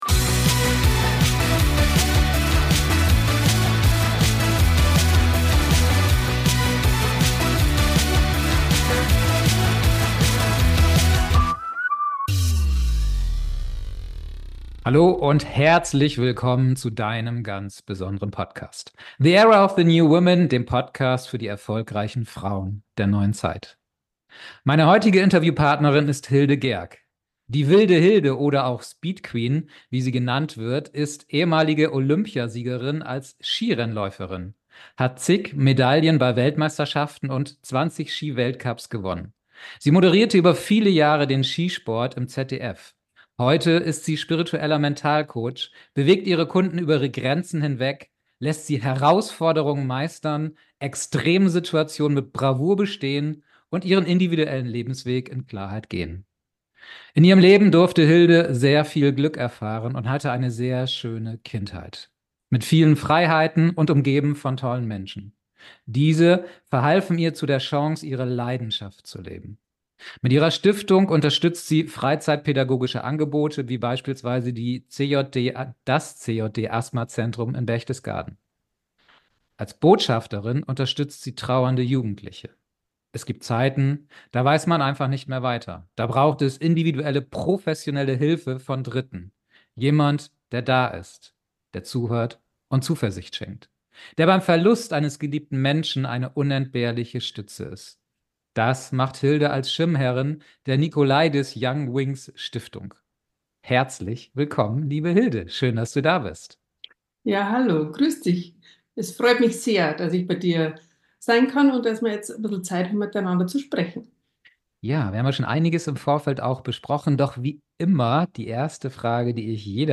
#035 Versagensängste und Selbstzweifel erfolgreich meistern. Das Interview mit Olympiasiegerin Hilde Gerg ~ The Era of the New Women Podcast